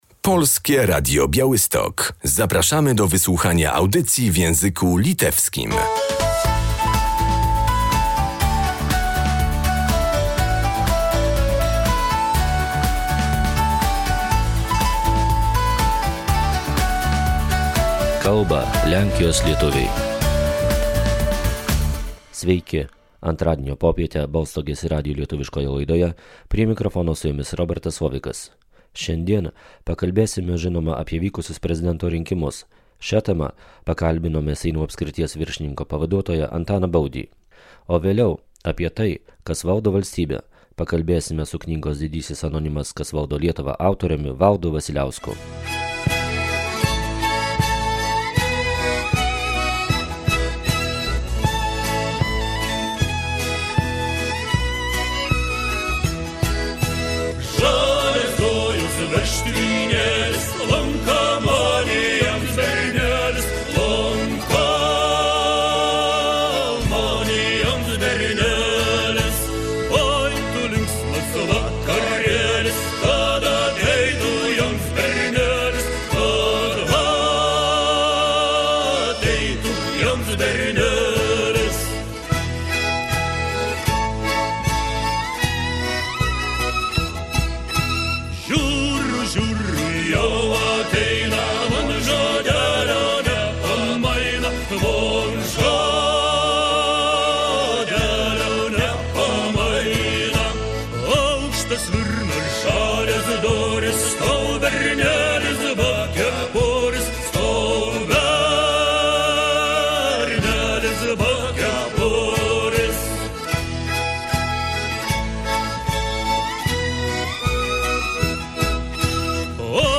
Wynik wyborów prezydenckich raczej nie powinien być zaskoczeniem. Takie wnioski można wyciągnąć z rozmowy z zastępcą Starosty powiatu sejneńskiego Antonim Buzdzis. Jego zdaniem, zmiany polityczne w kierunku prawej strony są widoczne w całej Europie.